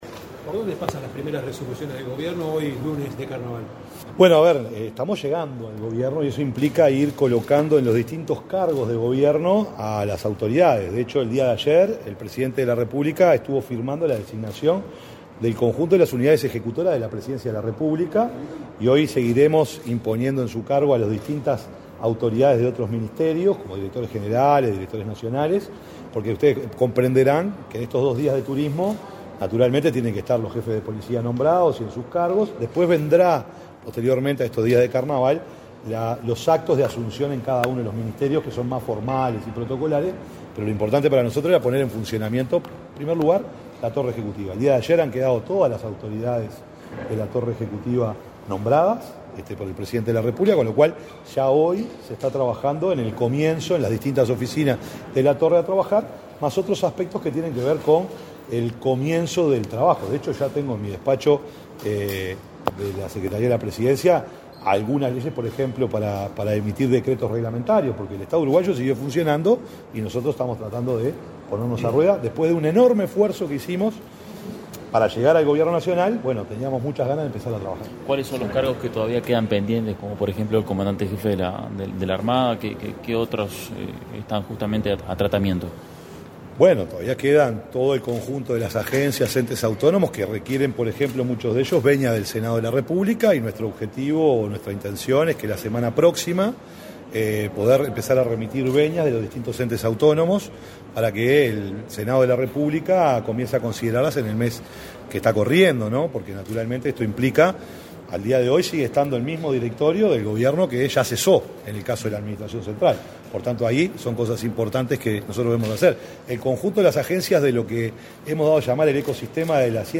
Declaraciones a la prensa del secretario de Presidencia, Alejandro Sánchez
El secretario de la Presidencia de la República, Alejandro Sánchez, realizó declaraciones a la prensa al arribar a la Torre Ejecutiva, este 3 de marzo